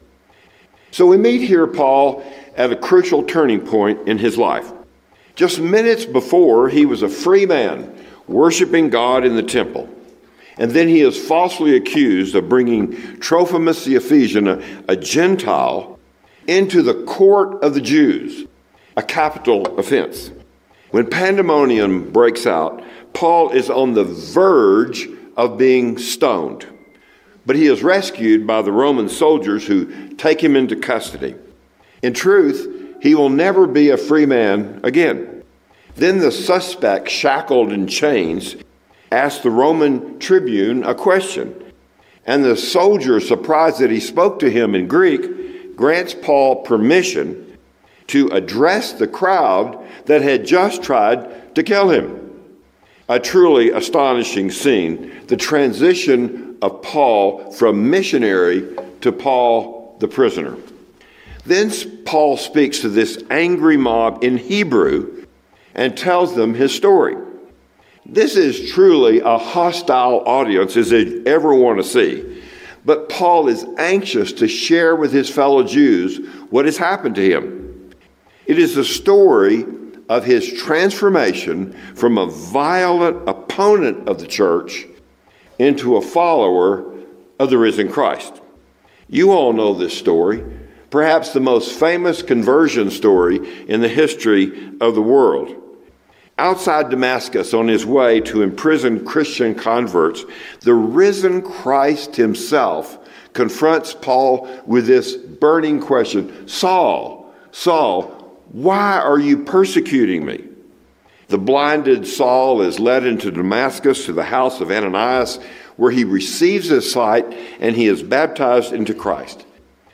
Services and Sermons Archive